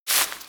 SandStep1.wav